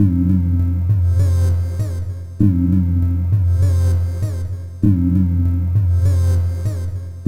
UFO_Energy.R.wav